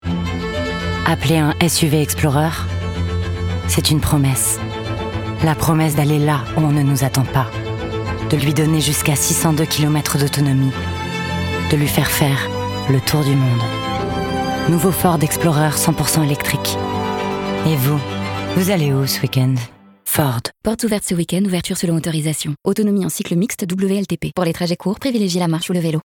Voix off
25 - 60 ans - Basse Mezzo-soprano